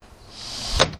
scsm_door1w.wav